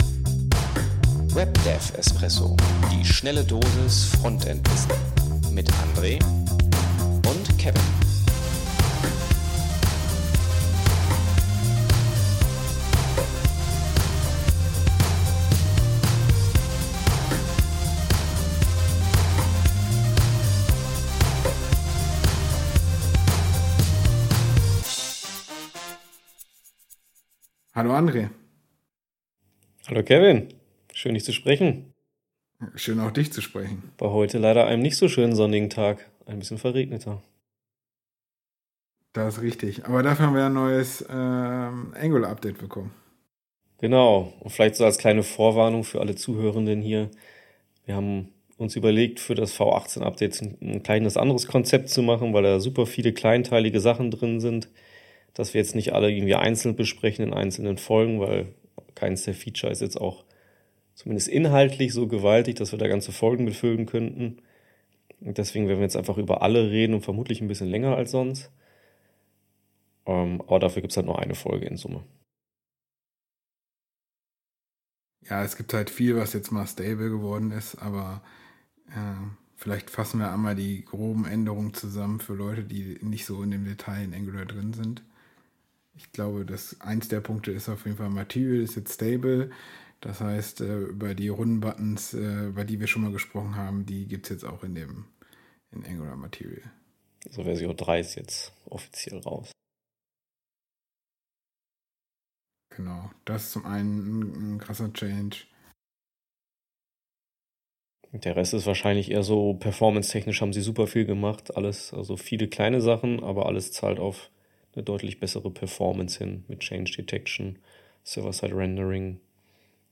Viele Neuerungen gibt es in der Version 18, die wir uns in dieser längeren Folge einmal anschauen. Lasst gerne Feedback da, wie euch das längere und weniger gescriptete Format gefällt.